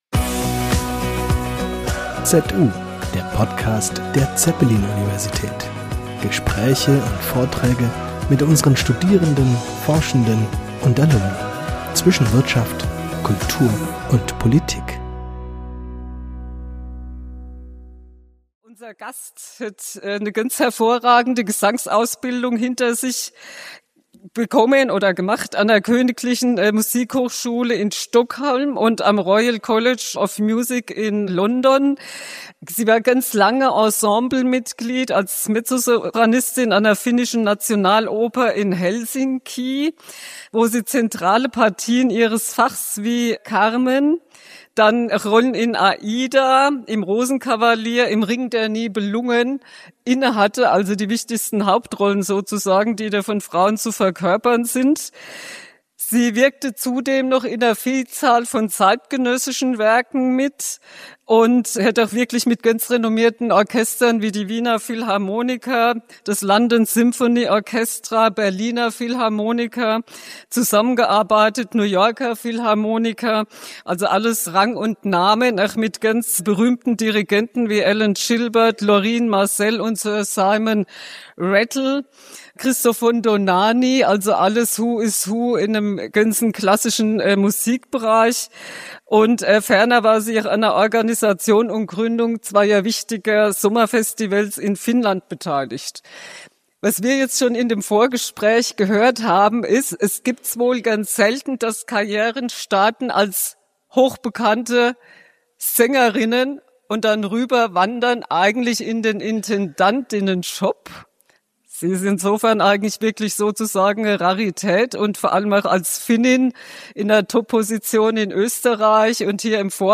Ein Gespräch mit Lilli Paasikivi | BürgerUniversität ~ ZU - Der Podcast der Zeppelin Universität Podcast